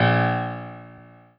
piano-ff-14.wav